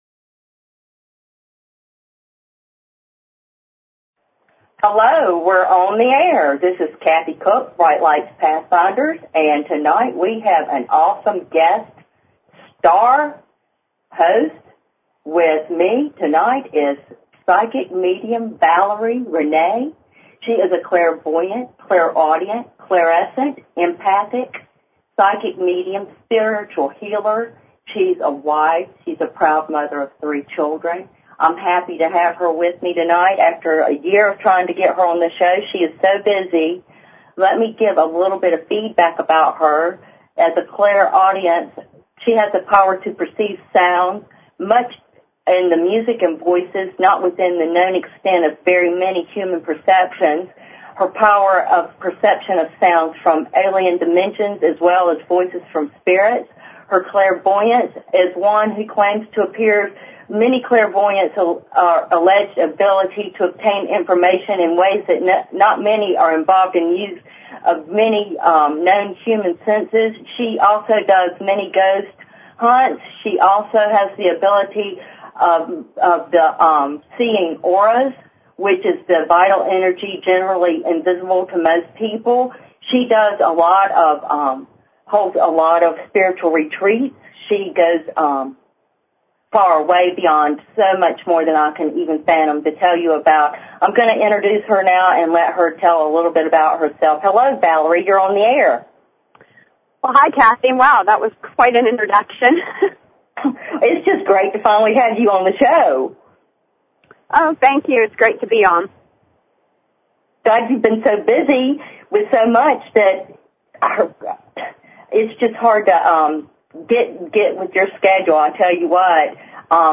Talk Show